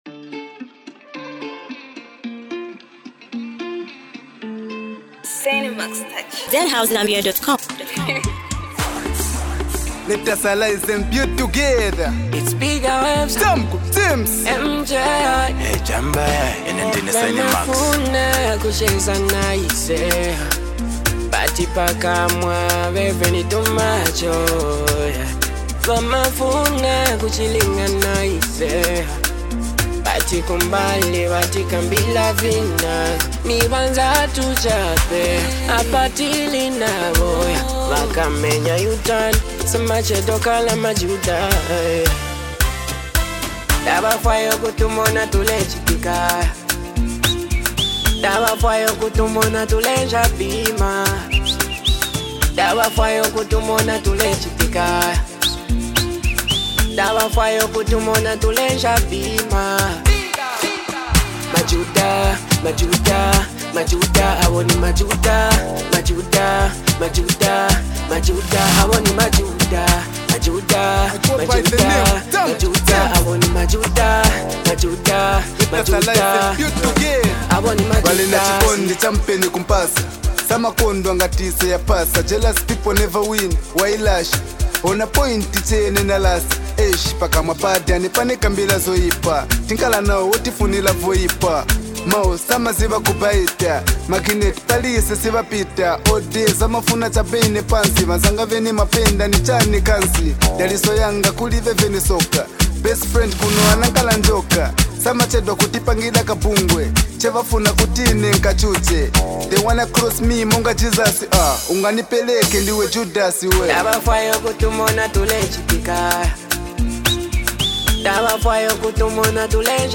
With hard-hitting lyrics and a captivating beat